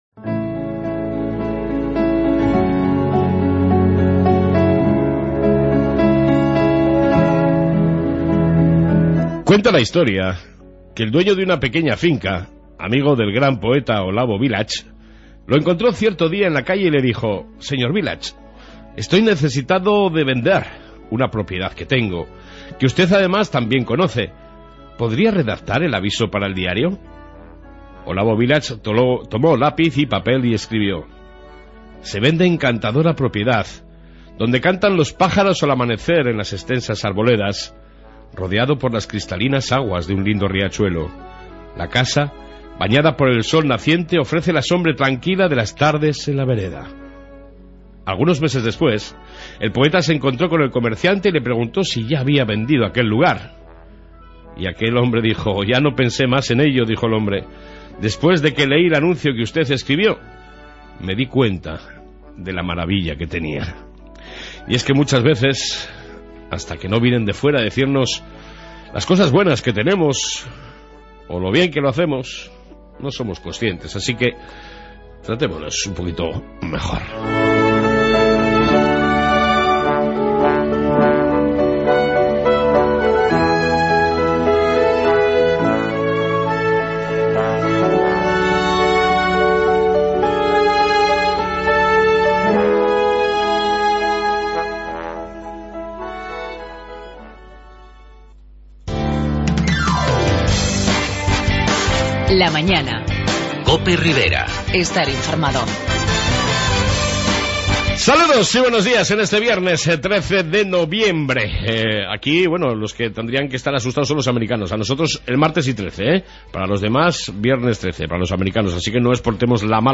AUDIO: Reflexión diaria y amplia entrevista con Javier Esparza, presidente de UPN